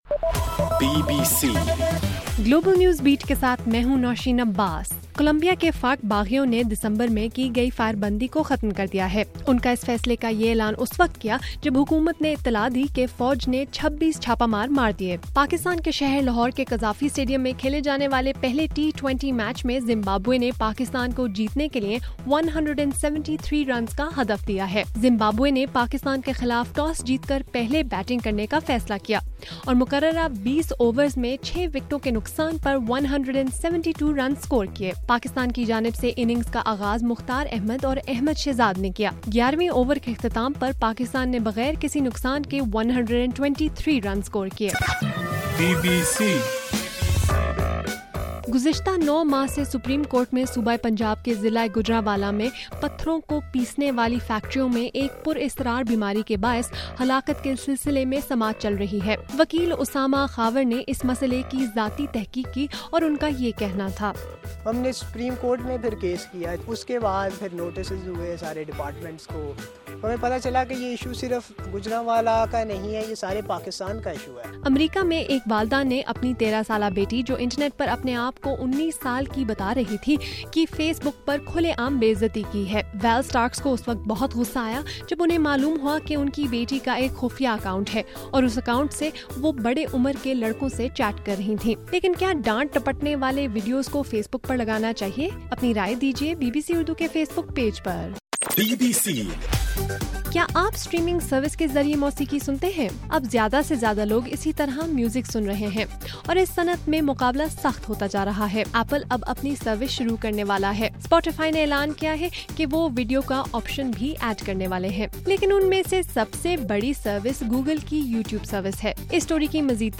مئی 22: رات 11 بجے کا گلوبل نیوز بیٹ بُلیٹن